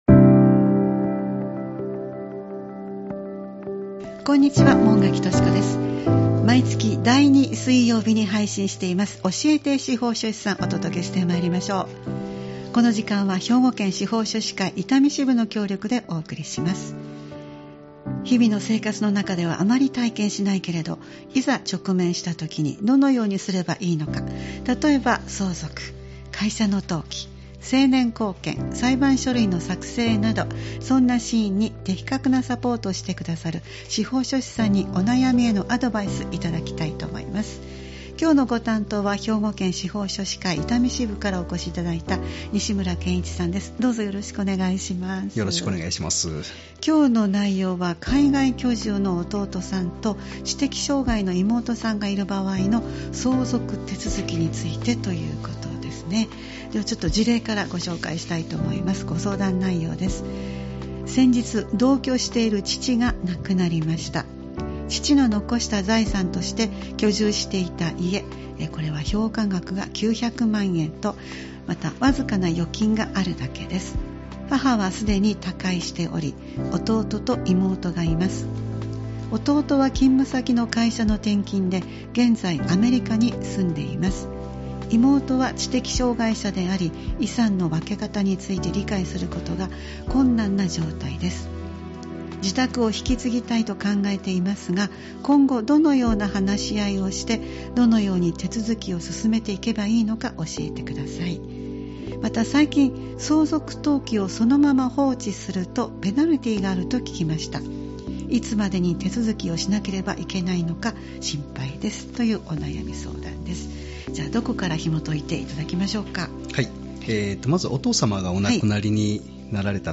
毎月第2水曜日は「教えて司法書士さん」を配信しています。毎回スタジオに司法書士の方をお迎えして、相続・登記・成年後見・裁判書類の作成などのアドバイスを頂いています。